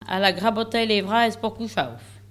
Locutions vernaculaires